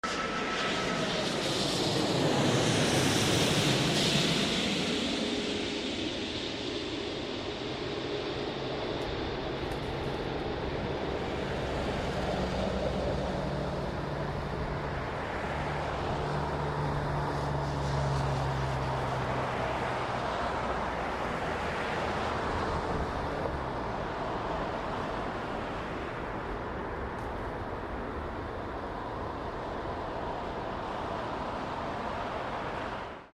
Fantastic A380 Landing Heathrow 27L